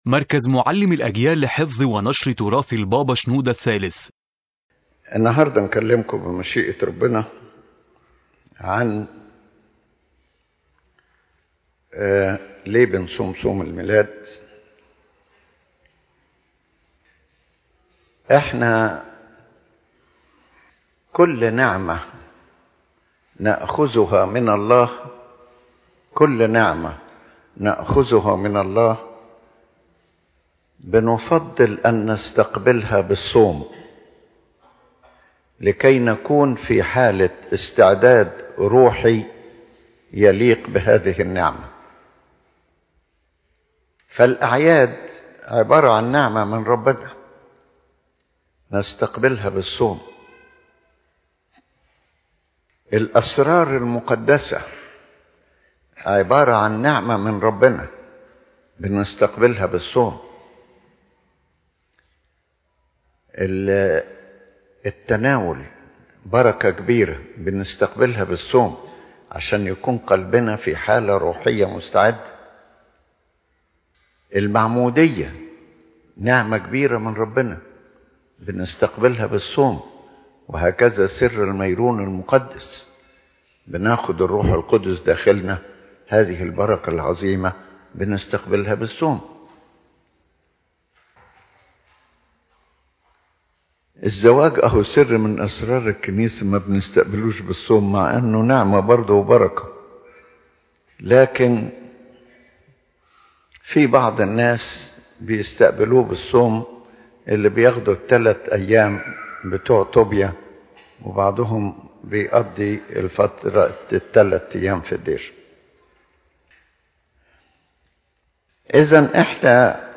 His Holiness Pope Shenouda III explains that fasting is a means of spiritual preparation to receive God’s blessings, and the Nativity Fast is a special preparation to receive the greatest grace, which is the Divine Incarnation. Every divine gift requires the preparation of the heart and mind through prayer, fasting, and repentance.